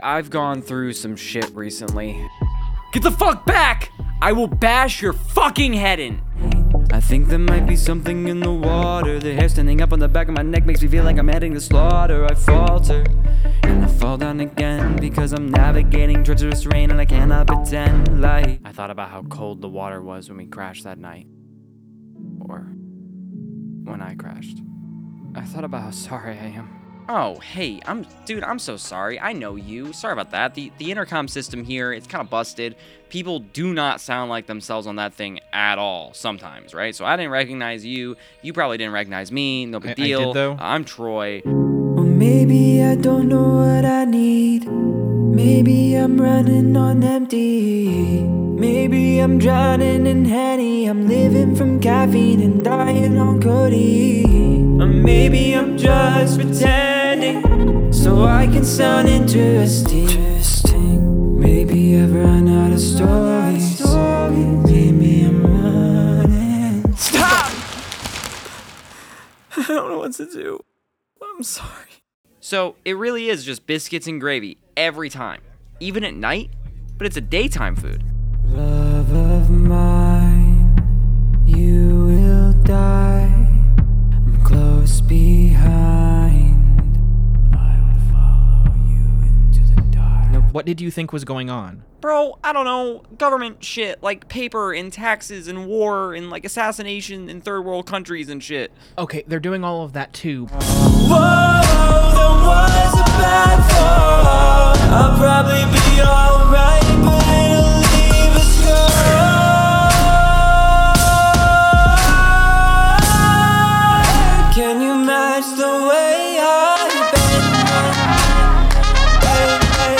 Voice Demo Reel